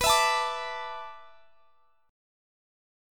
Dsus2/B Chord (page 3)
Listen to Dsus2/B strummed